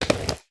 SD_SFX_RollerSkate_Step_1.wav